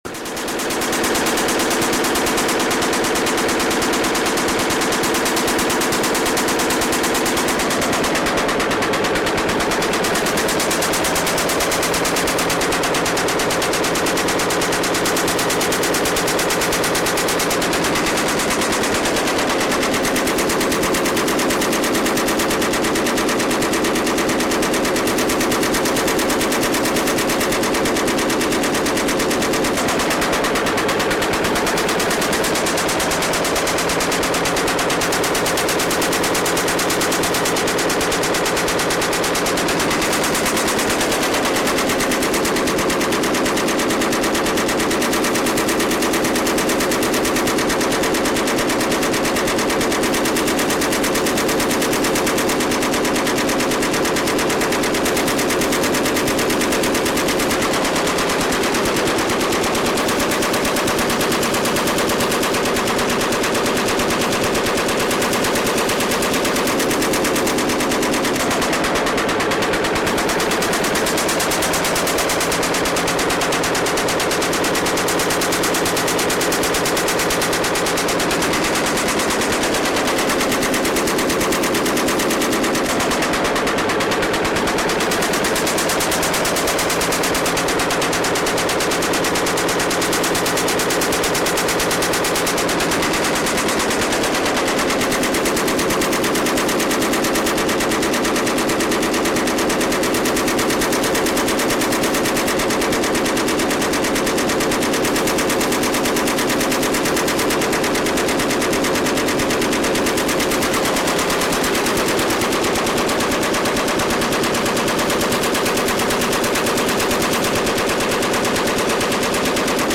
Someone asked for a looped MP3 and this is the best I could do, music to my ears! M60 E4 Loop
M60-E4.mp3